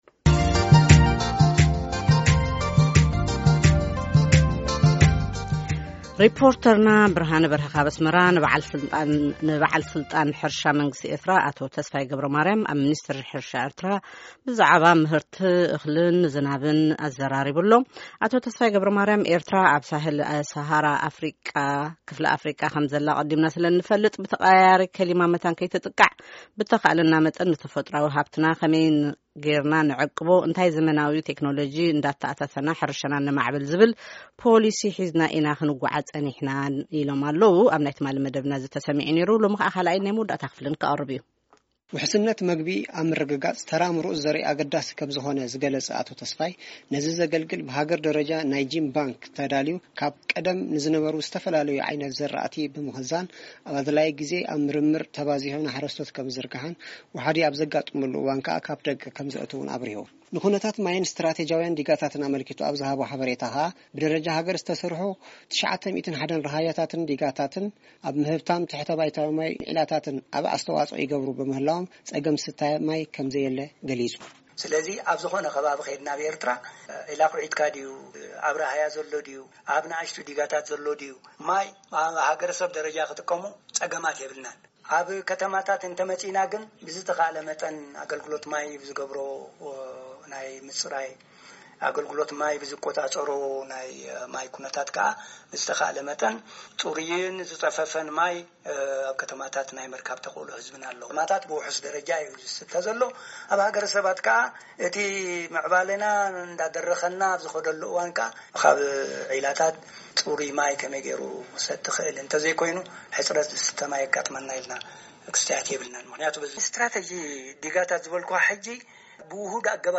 ኣብ ቀርኒ ኣፍሪቃ ሚልዮናት ሰባት ኣብ ሃገራቶም ብዘጋጠመ ደርቂ፡ ኣብ ሓደጋ ጥምየትን ሕጽረት መግብን ተሸሚሞም ይርከቡ። ኣብ`ቲ ዞባ ትርከብ ኤርትራ`ኸ ኣብ ምንታይ ኩነታት ትርከብ ንዝብል ሕቶ ንምምላስ ካብ ዝግበር ጻዕሪ ሓደ ንበዓል-ስልጣን ሕርሻ መንግስቲ ኤርትራ ኣዘራሪብና`ለና።